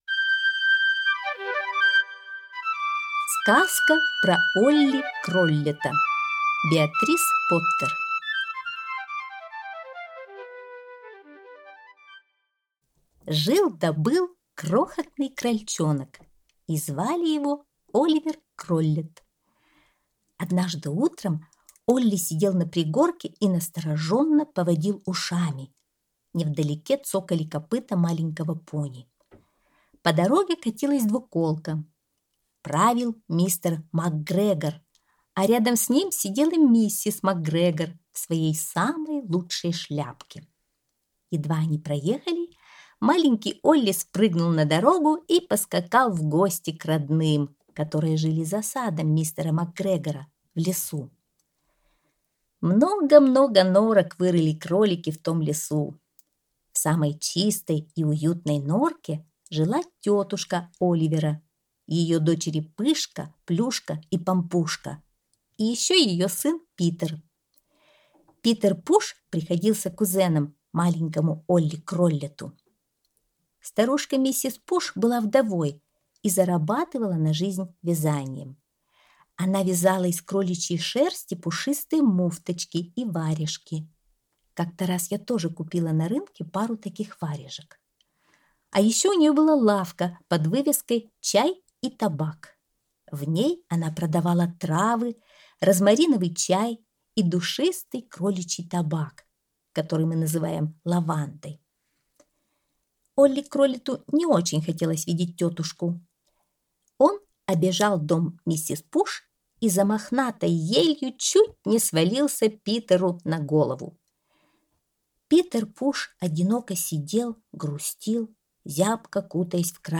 Сказка про Олли Кроллета - аудиосказка Беатрис Поттер - слушать онлайн